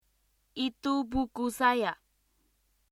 例えば，Itu buku saya.（それは私の本です。）という文のイントネーションは，２つのポーズグループ，「itu（それ）」と「buku saya（私の本）」の組み合わせで，以下のようになります。ポーズグループの間にはポーズ（イメージでは赤斜線）が入ります。